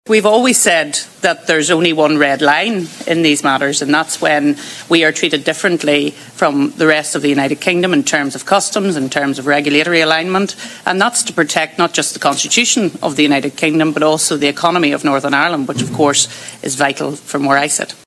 Arlene Foster’s been speaking after a meeting with the EU’s chief negotiator Michel Barnier in Brussels today.